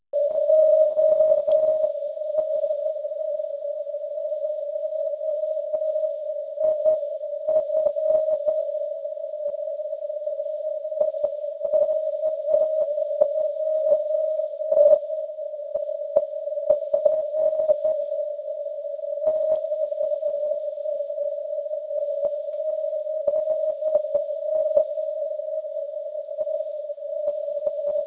RIG FT950 s vypnutým AGC, filtr 100 Hz na DSP, bez předzesilovačů, ANT G5RV, nahrávka přes repro/mikro cestu na mobila .
80 microwatt maják (wav)